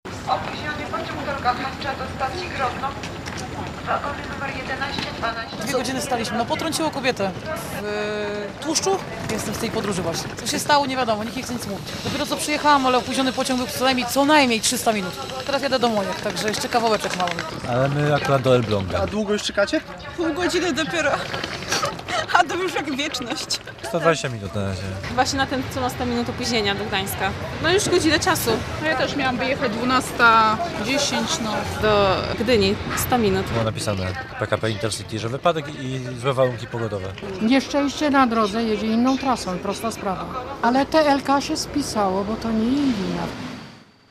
Podróżni jadący z Białegostoku czekają na pociągi - relacja